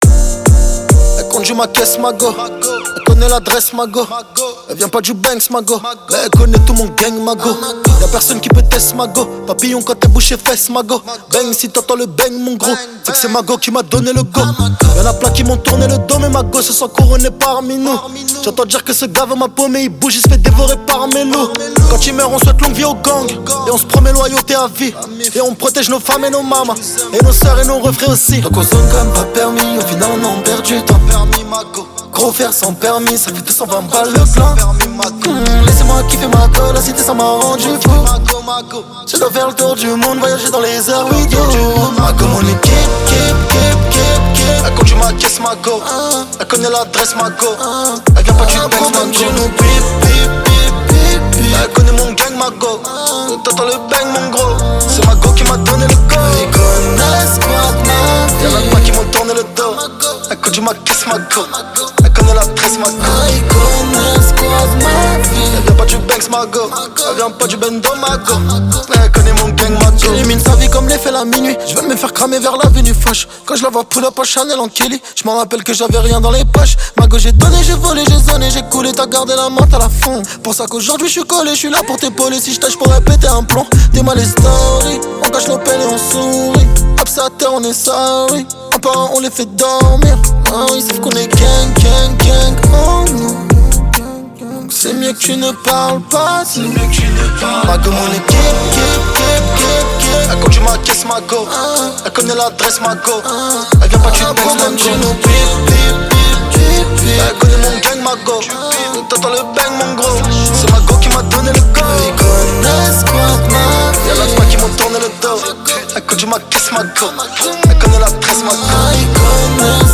Genres : french rap, pop urbaine